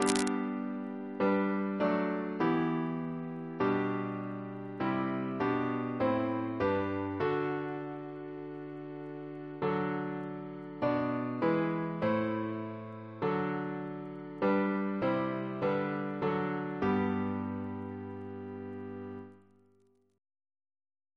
CCP: Chant sampler
Double chant in G Composer: Thomas Norris (1741-1790), Composer of songs and tenor singer Reference psalters: ACB: 35; ACP: 71; CWP: 206; H1982: S207; OCB: 136; PP/SNCB: 131; RSCM: 104